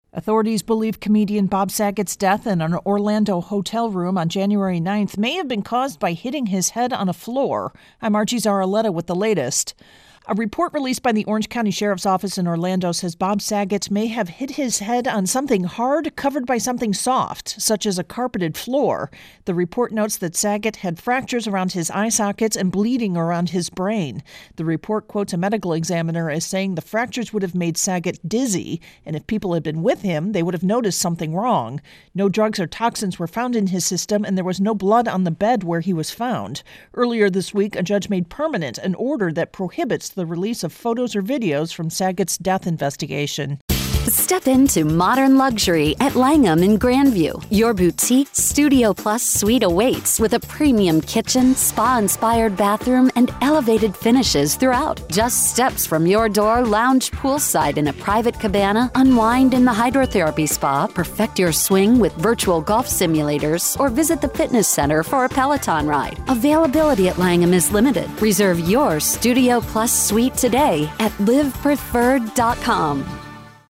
intro & voicer for Bob Sagat